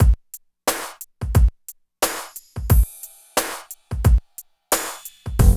16 DRUM LP-R.wav